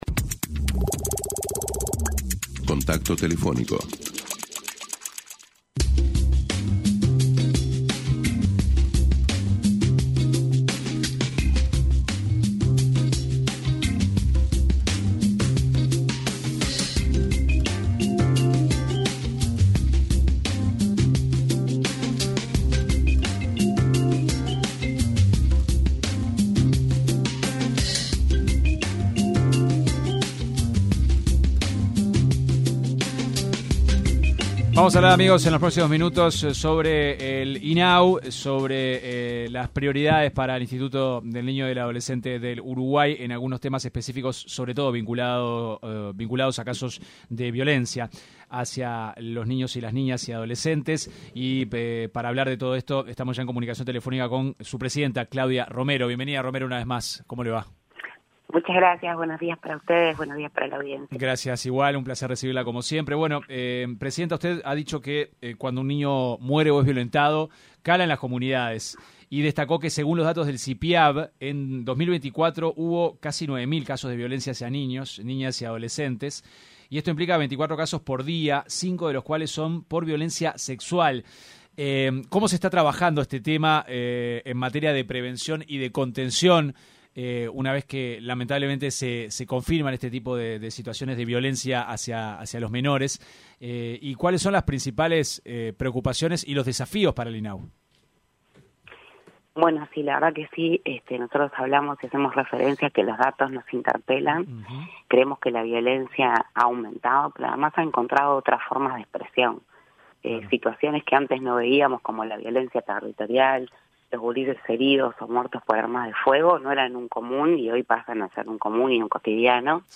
La presidenta del Instituto del Niño y del Adolescente del Uruguay (Inau), Claudia Romero, se refirió en una entrevista con 970 Noticias, a cómo se trabaja desde el organismo en materia de prevención y contención de la violencia hacia los niños, niñas y adolescentes.